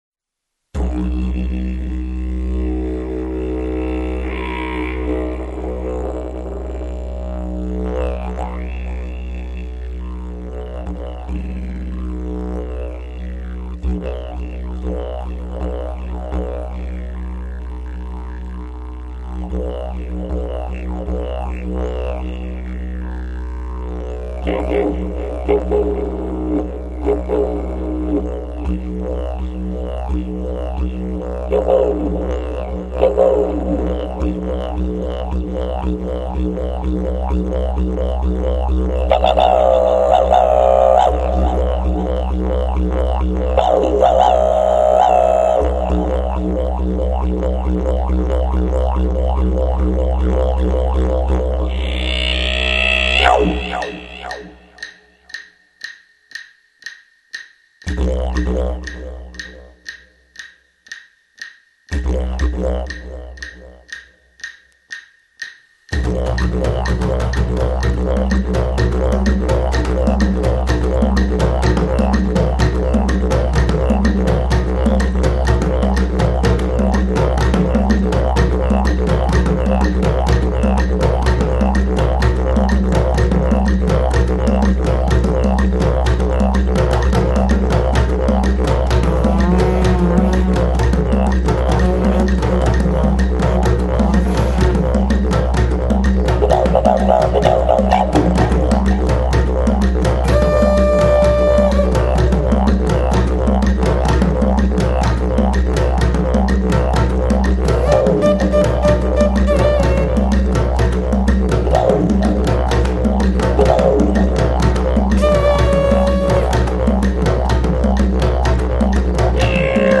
( Didjeridoo, percussioni africane e conchighie).